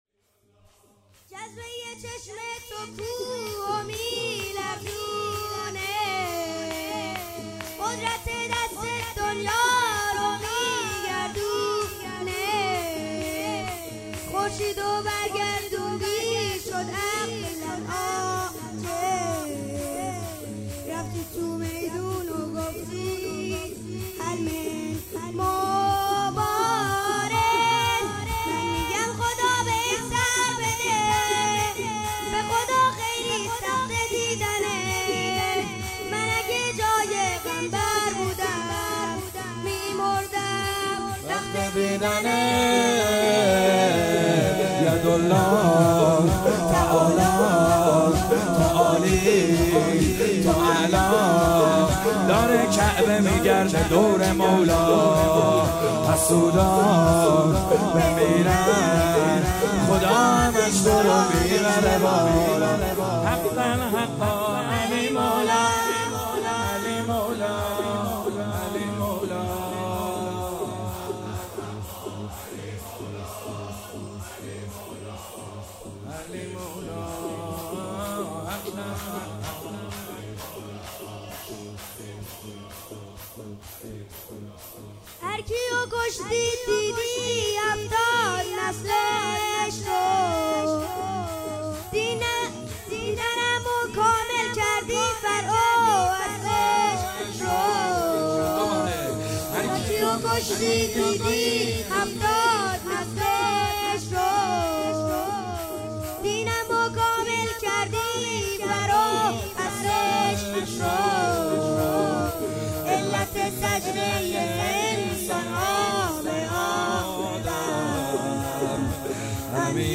کربلایی محمدحسین حدادیان
شب چهارم فاطمیه دوم 1402 -شور - جذبه ی چشم تو کوه میلرزونه - محمد حسین حدادیان
شب چهارم فاطمیه دوم صوتی